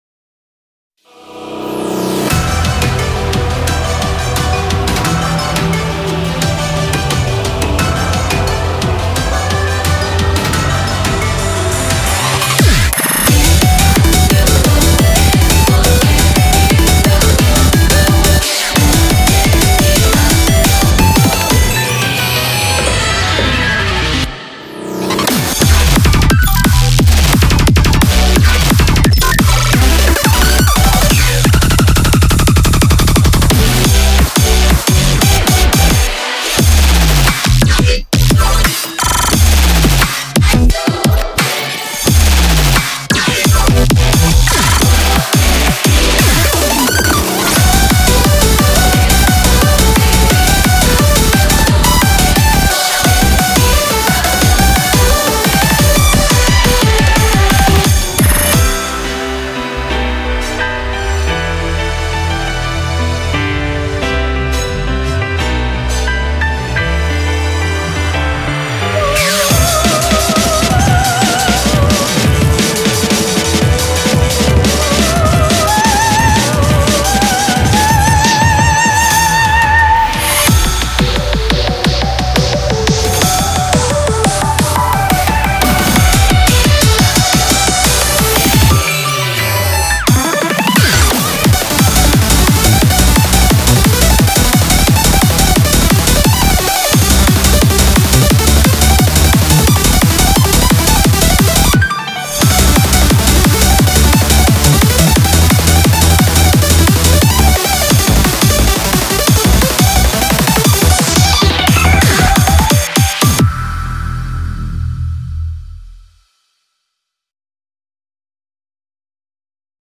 BPM88-175
Audio QualityPerfect (High Quality)
Genre: XYMPHONIC TECHCORE